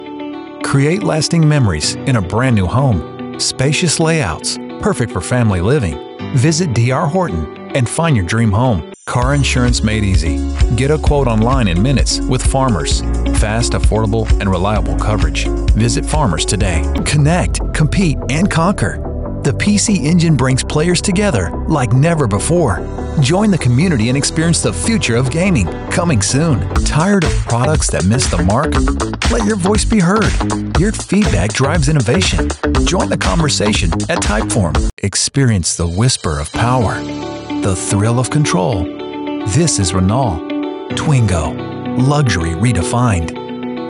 I can deliver a wide range of styles, from friendly and conversational to authoritative and dramatic.
CORPORATE, Conversational, Upbeat, Real Person, Professional, Business, Demo
American General accent, American Southern accent, American West accent, American West Coast accent